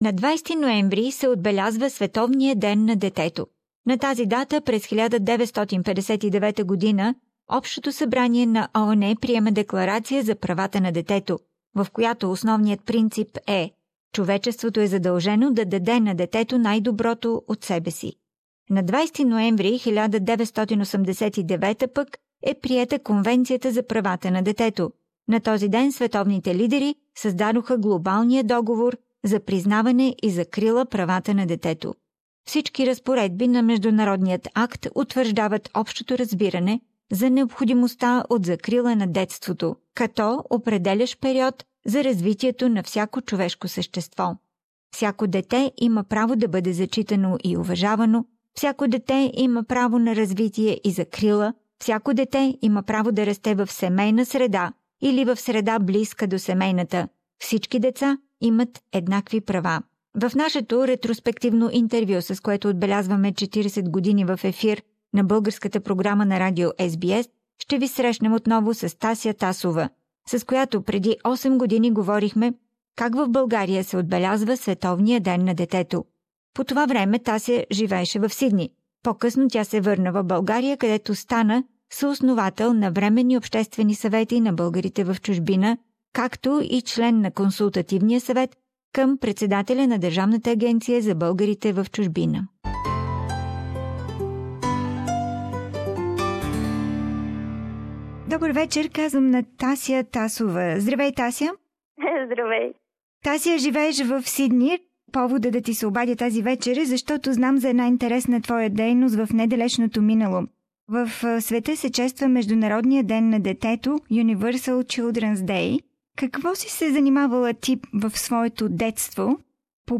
In our flashback interview